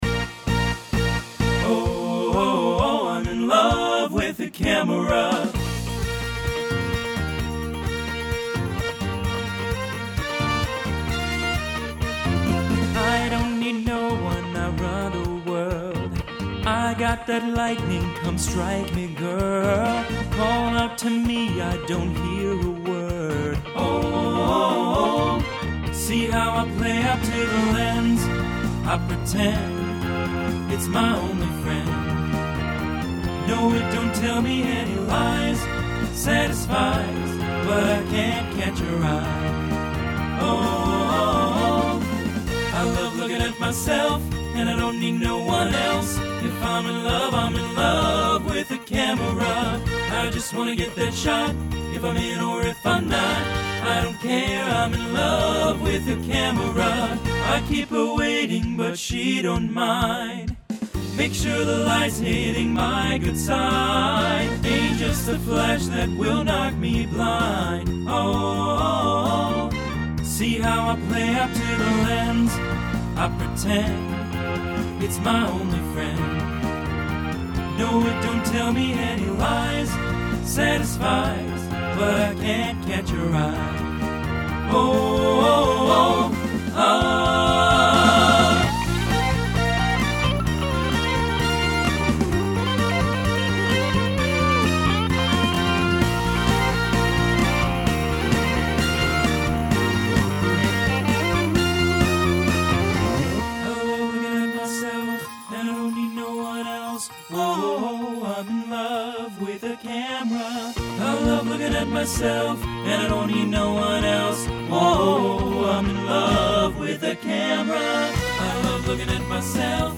Genre Rock Instrumental combo
Voicing TTB